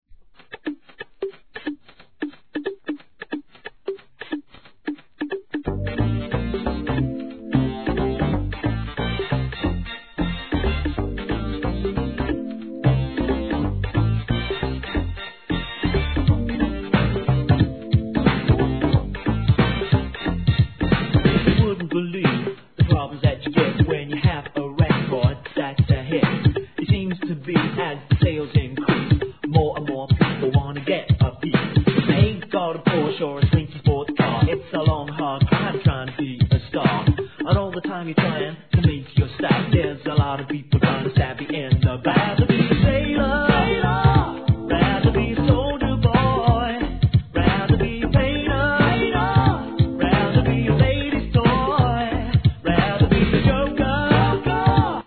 HIP HOP/R&B
RAP & ヴォーカルで聴かせる